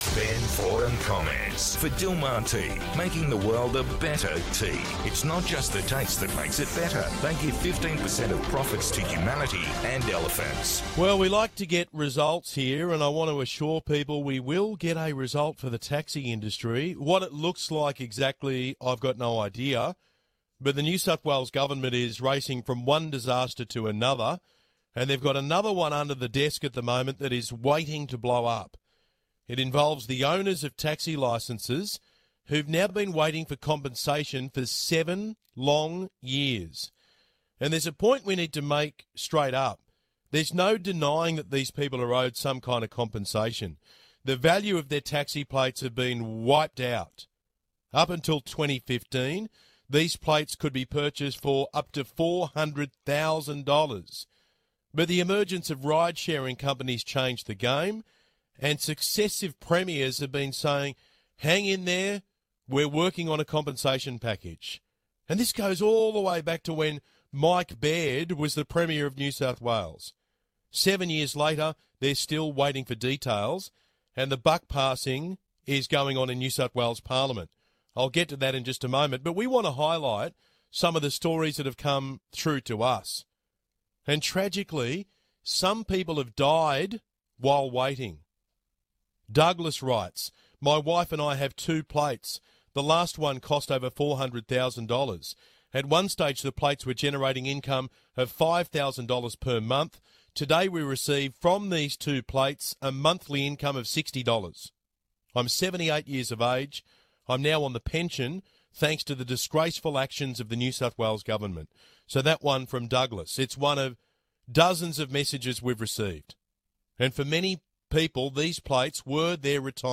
Tuesday 2nd August, 2022 Source: 2GB 2GB's Ben Fordham continued the fight for fair and proper compensation for NSW Taxi Licence Owners on his breakfast program. Ben shared stories from Taxi Licence Owners on how the NSW Government's decisions have impacted their lives. Ben is calling on the NSW Treasurer Matt Kean to solve this issue that has been going on for 7 years.